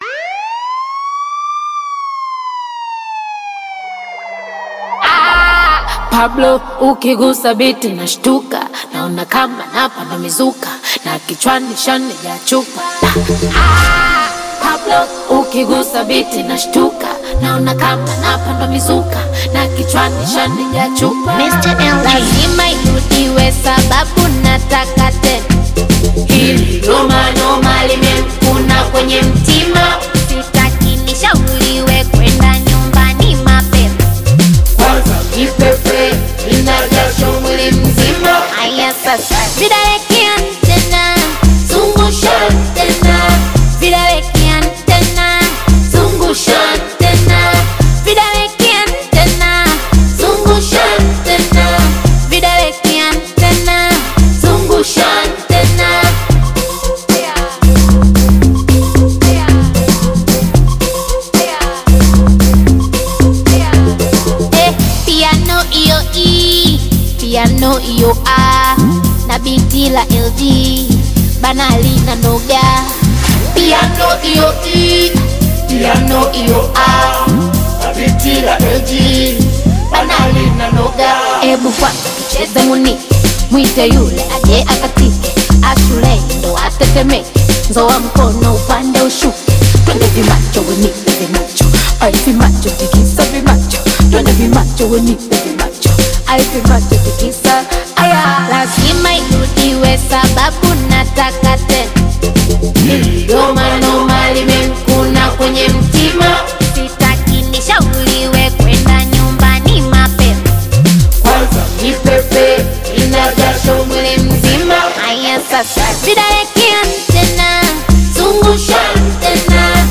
high-energy Afro-Pop/Bongo Flava single